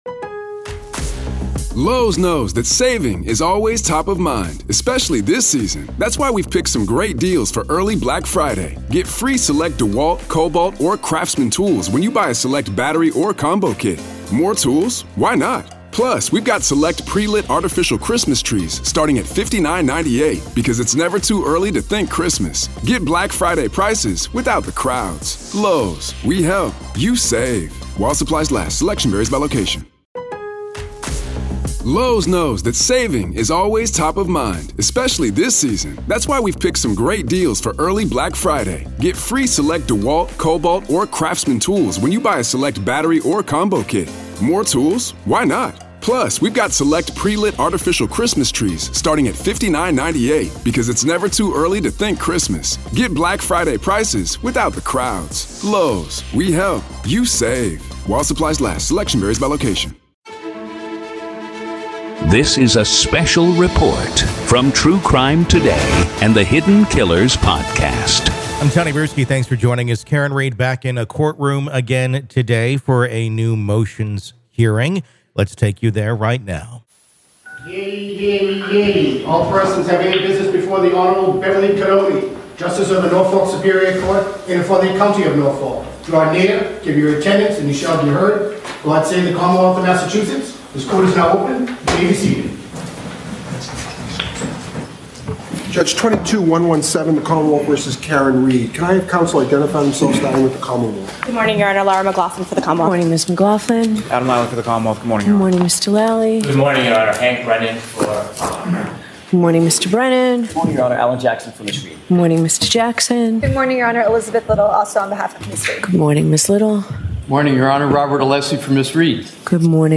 RAW COURT AUDIO: Judge Halts Karen Read Pretrial Hearing After ‘Grave Concern’ Over New Information PART 1